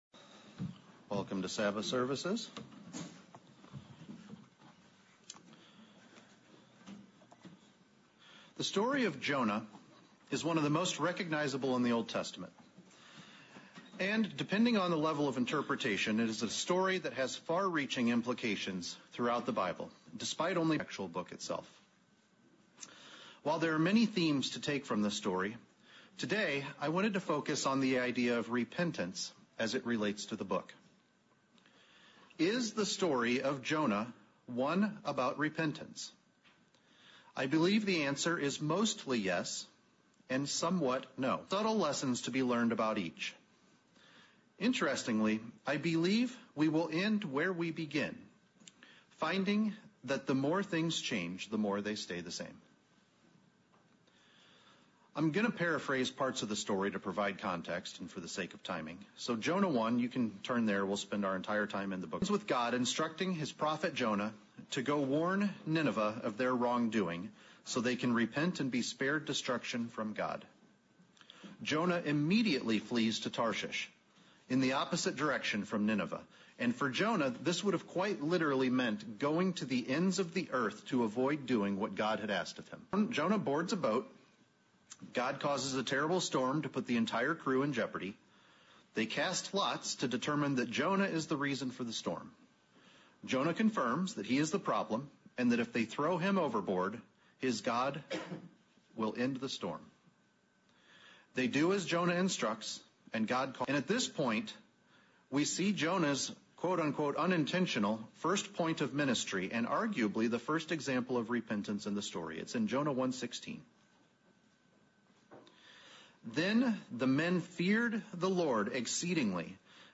Sermonette looking at the story of Jonah. Does this book tell of a story of repentance or something different? What lessons can we learn from this book?